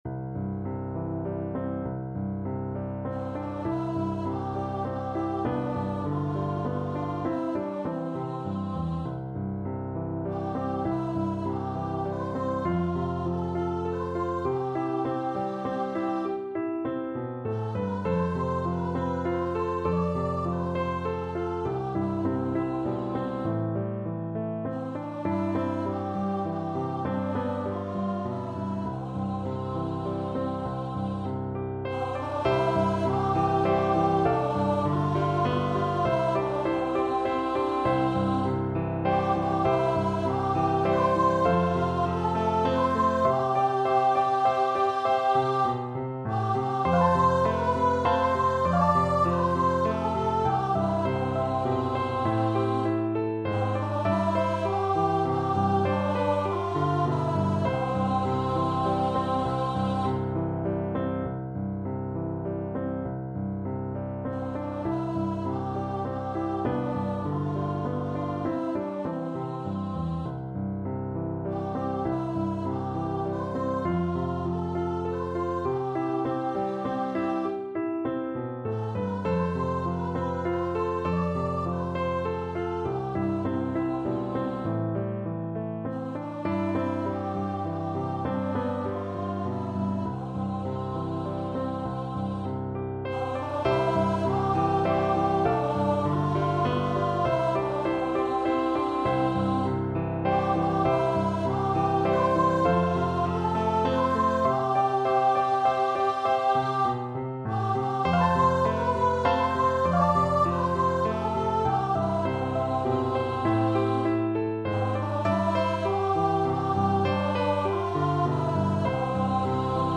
Longingly, nostalgically =c.100
3/4 (View more 3/4 Music)